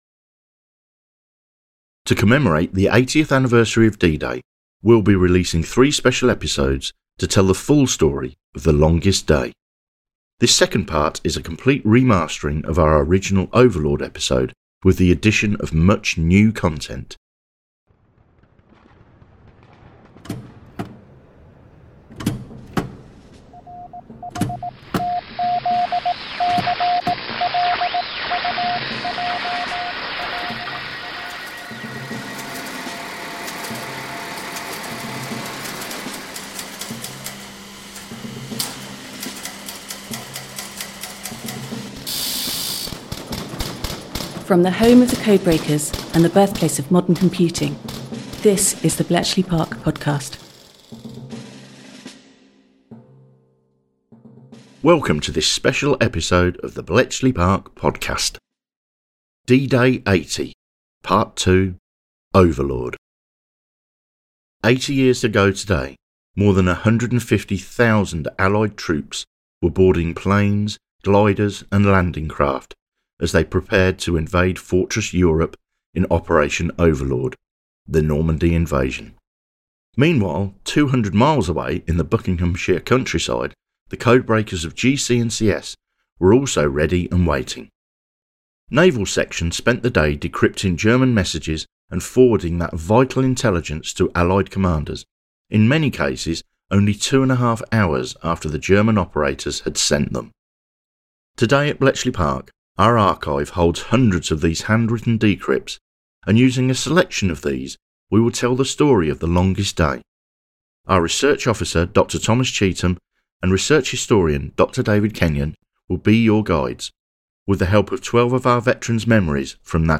This second part is a complete remastering of our original Overlord episode with the addition of much new content. 80 years ago today, more than 150,000 Allied troops were boarding planes, gliders and landing craft as they prepared to invade Fortress Europe in Operation Overlord, the Normandy Invasion.